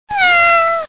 cat_meow.wav